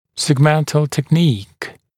[segˈmentl tek’niːk][сэгˈмэнтл тэк’ни:к]сегментарная техника